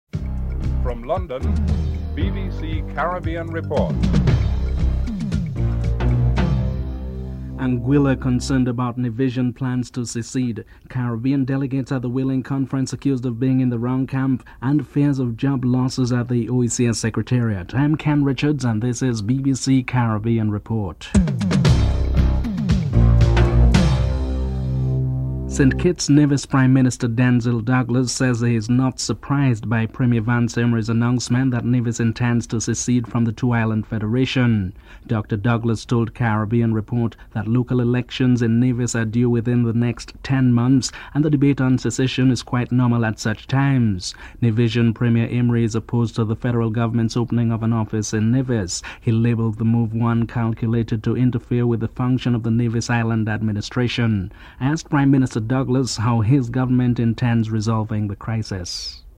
In Trinidad and Tobago both major political parties are engaged in a clash of words of who put up a better showing in yesterday's local government elections. Opposition Political Leader Patrick Manning and Prime Minister Basdeo Panday are interviewed.